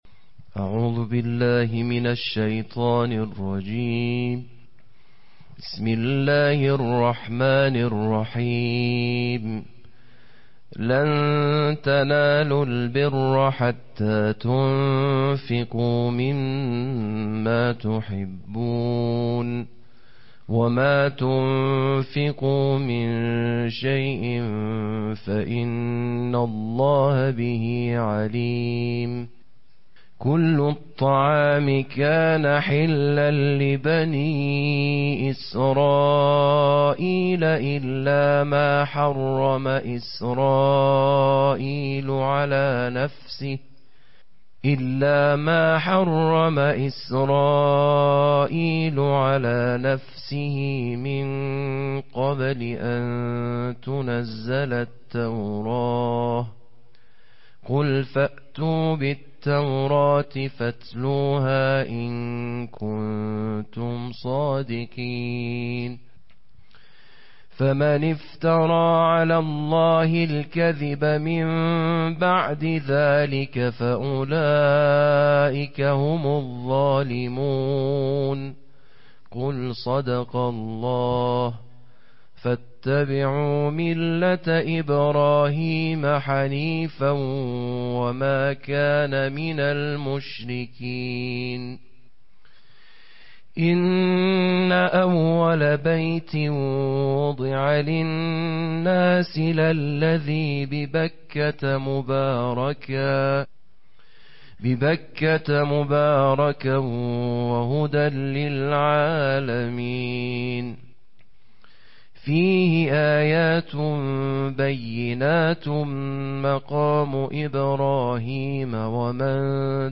Қироати тартили ҷузъи чоруми Қуръон бо садои қориёни байналмилалӣ + садо